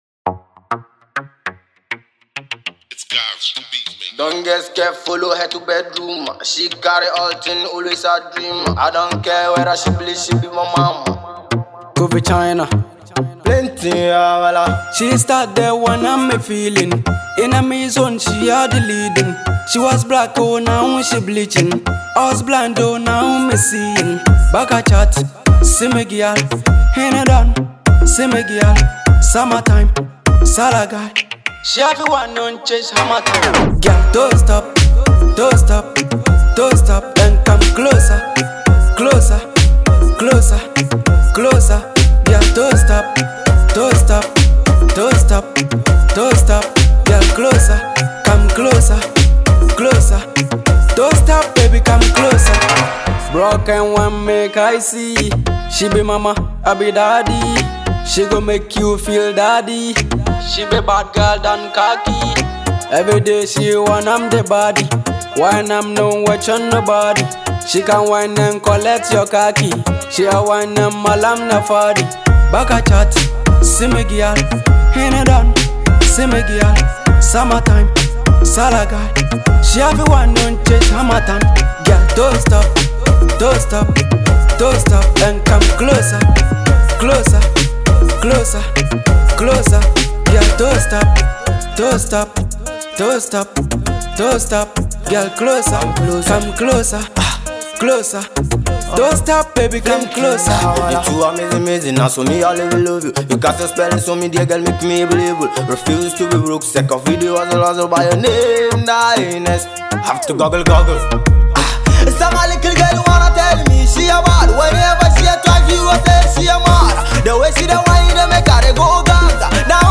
Ghanaian hardcore rapper